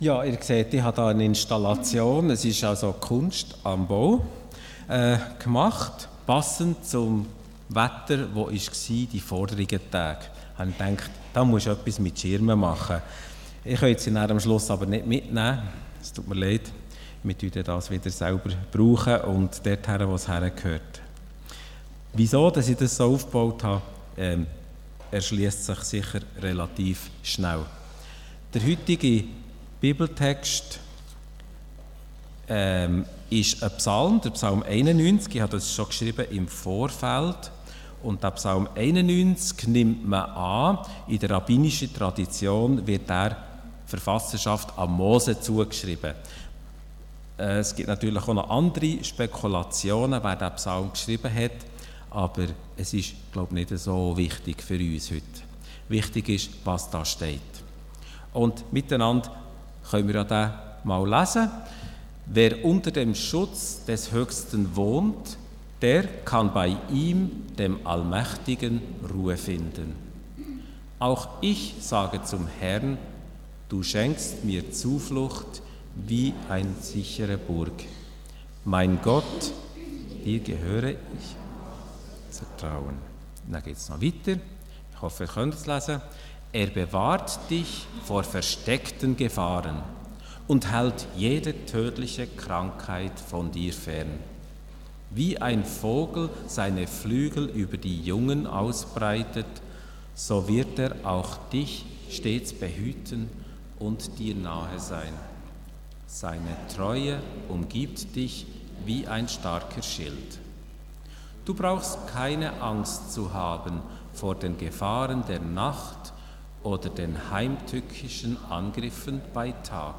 Von Serien: "Diverse Predigten"